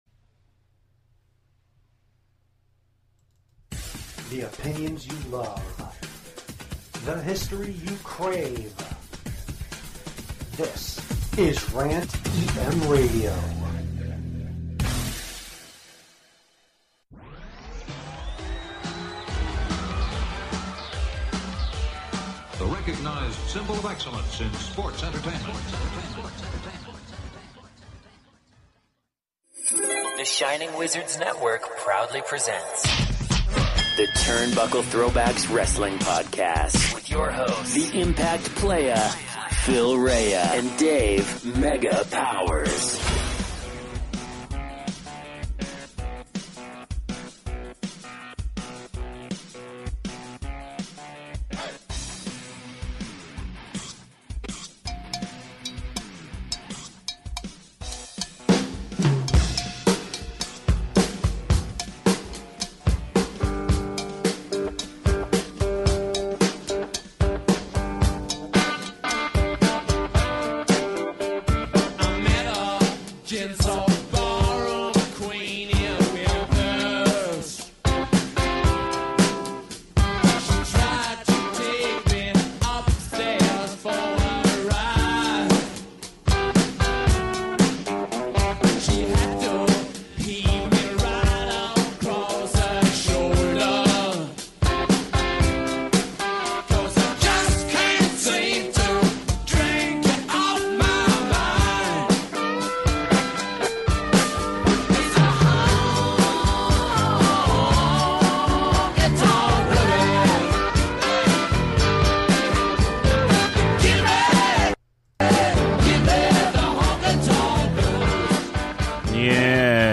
rides solo and rants about the 9-11 Museum, The Honky Tonk Man and a bunch of other nonsense. Open the phones line up